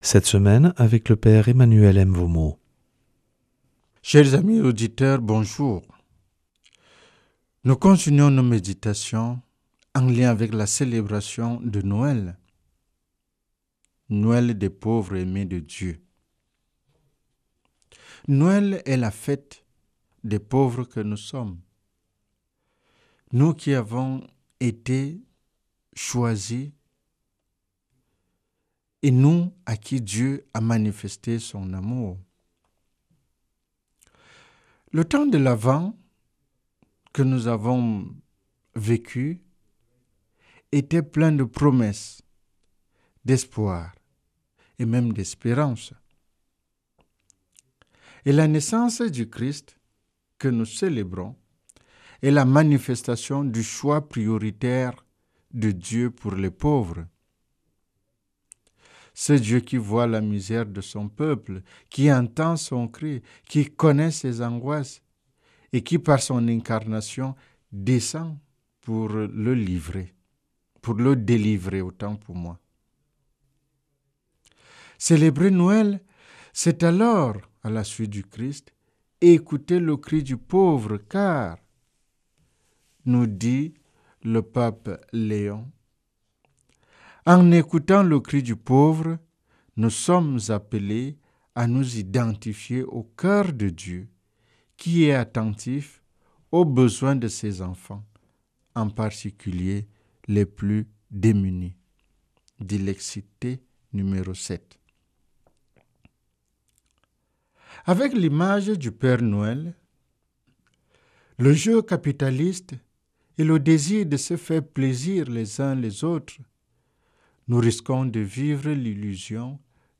vendredi 26 décembre 2025 Enseignement Marial Durée 10 min